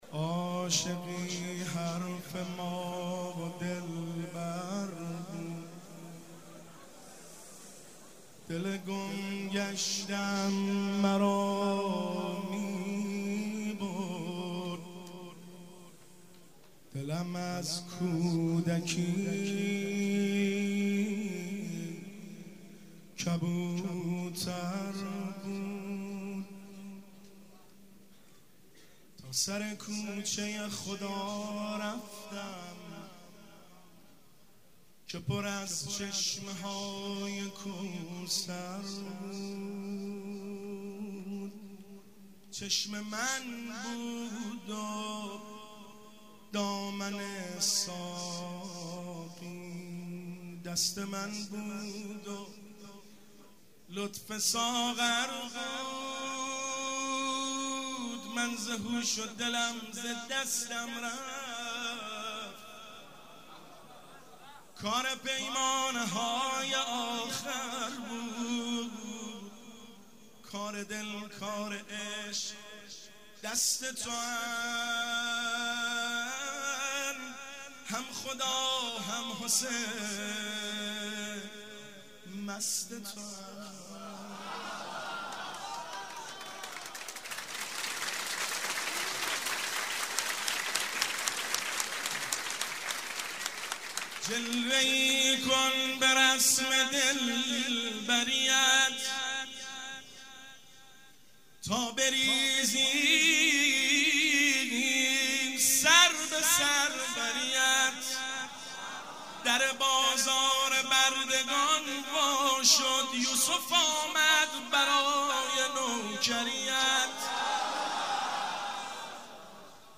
که در ریحانه الحسین اجرا شده است
( سرود)